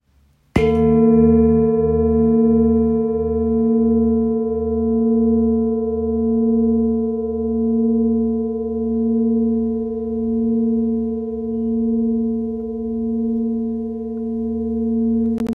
Large Etched Bowl with Seven Chakra Symbols Hindu – 40cm
The bowl measures 40cm in diameter.
Each bowl comes with a cushion and striker.